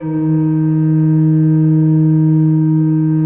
Index of /90_sSampleCDs/Propeller Island - Cathedral Organ/Partition L/ROHRFLUTE MR